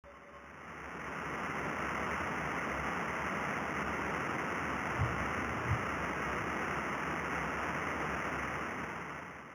Uncategorized Glitch
¶ Uncategorized Glitch (None of the Above) Description: This category is a catch-all for glitches that do not fit into the other categories, and therefore has much variability in its morphology.